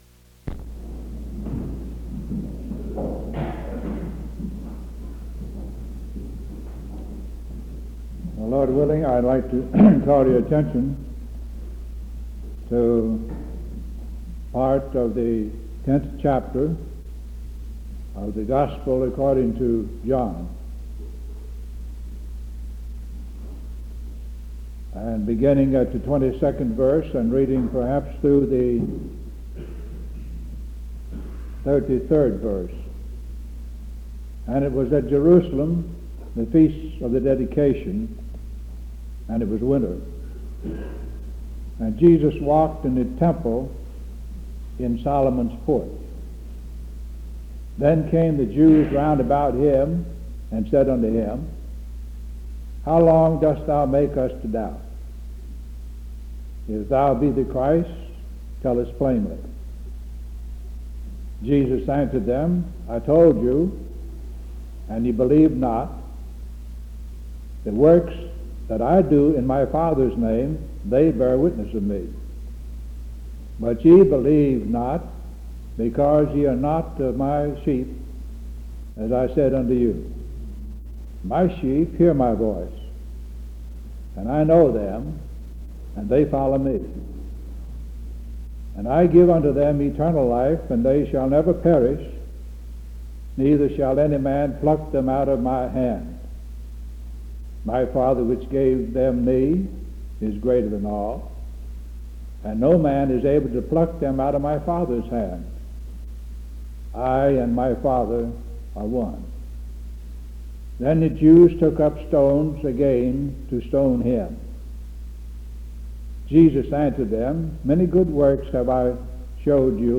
Primitive Baptists